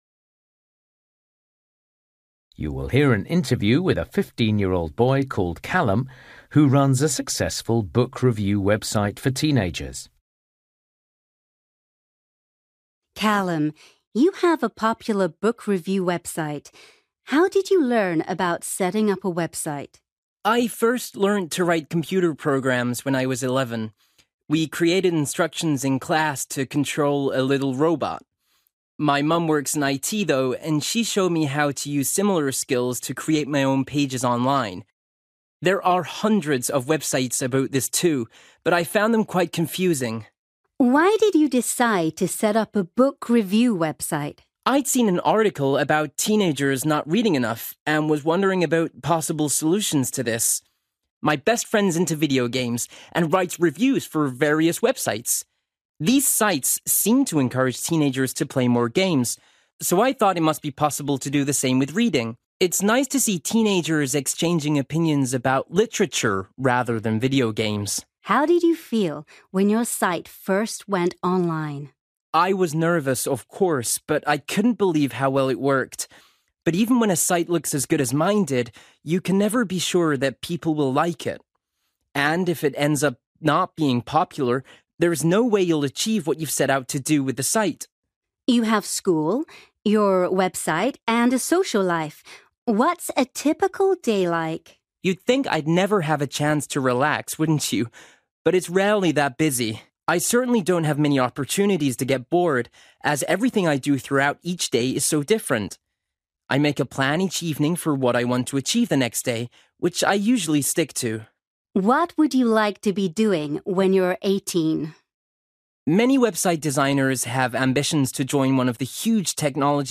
You will hear an interview